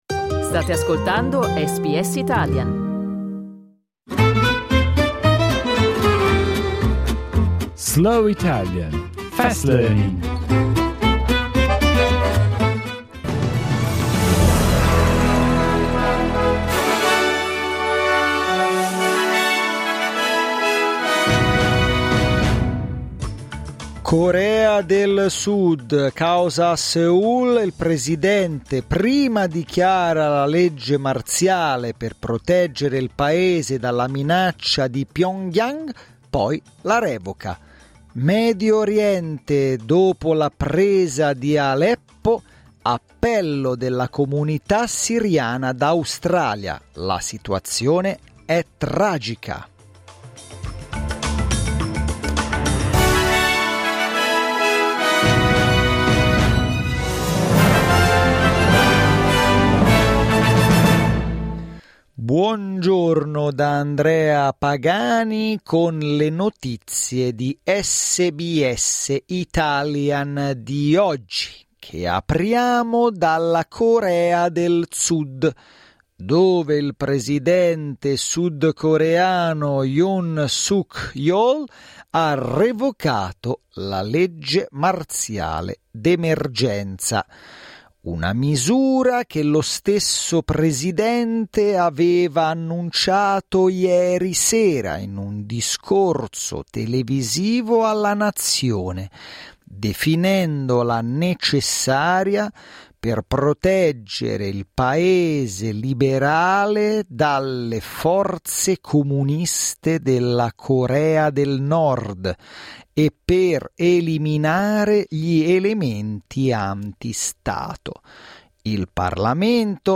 SBS Italian News bulletin, read slowly.